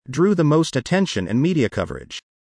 英語ニュースの穴埋めディクテーション・クイズ
▶弱音の「and」: 語尾の止めるDは聞こえない
１）弱音の「and」と「in」が、ほぼ同じ音に聞こえる。